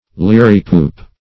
Liripoop \Lir"i*poop\ (l[i^]r"[i^]*p[=oo]p), n. [OF. liripipion,